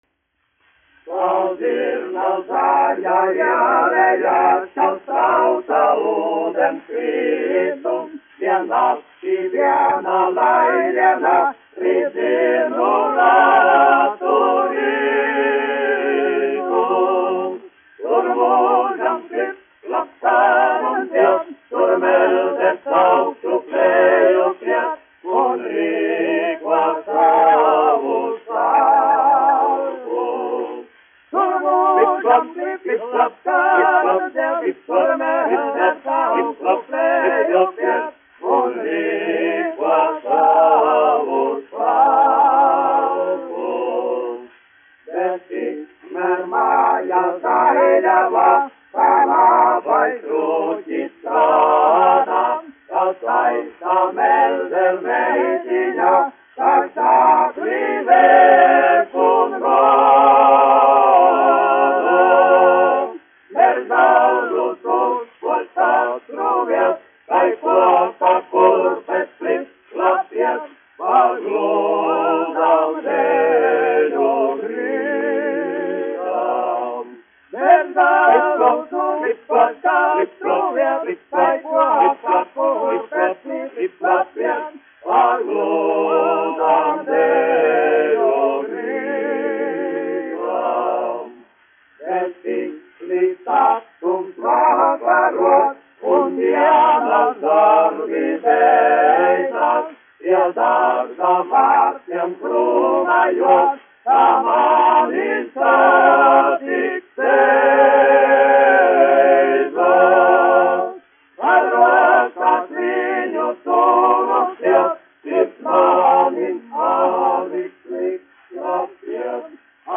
1 skpl. : analogs, 78 apgr/min, mono ; 25 cm
Vokālie seksteti
Populārā mūzika -- Latvija
Skaņuplate